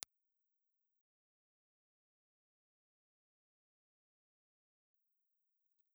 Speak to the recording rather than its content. Impulse Response file of Melodium RM6 ribbon microphone in 0.4m position Melodium_RM6_HPF_0.4_IR.wav